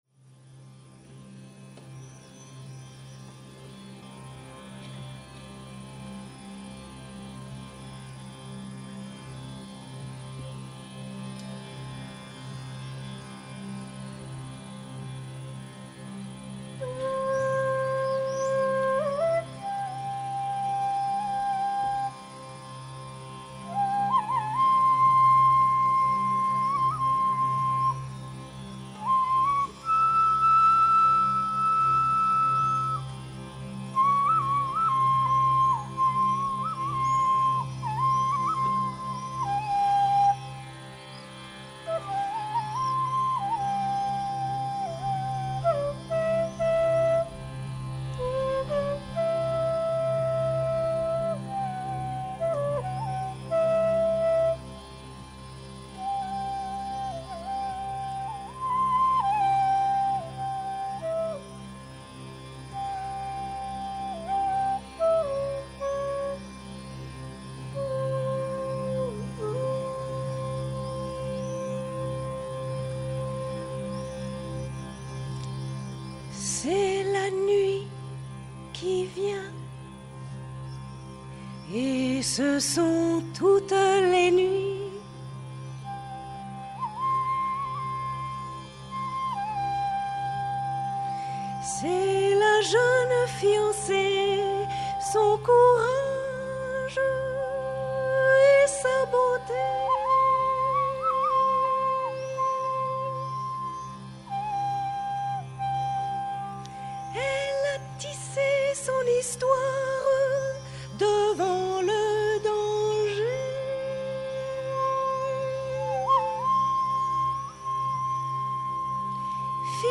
2020-01-10 Les nuits des 20, 22 et 24 juillet 1983, Le Récit de Shéhérazade était programmé au Festival d’Avignon et enregistré par France Culture. C’était la première fois que le Festival proposait un spectacle pendant toute une nuit, la première fois qu’il accueillait des conteurs.